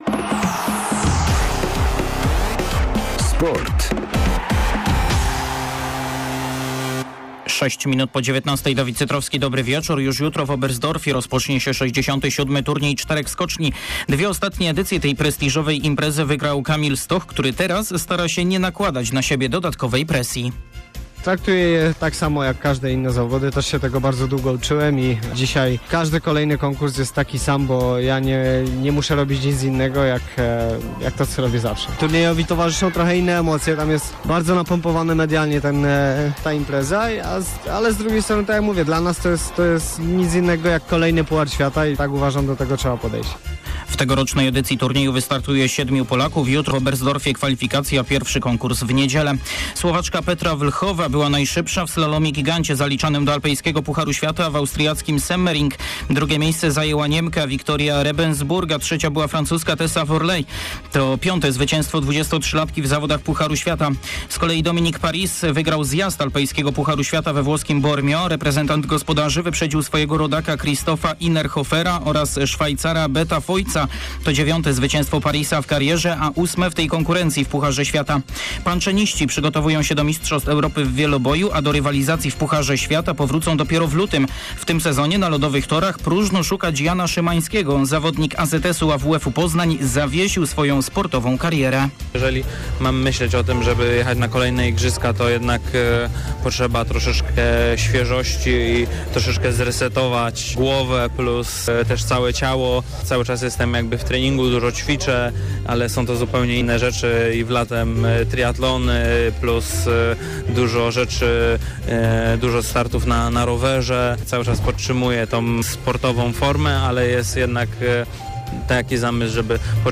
28.12. serwis sportowy godz. 19:05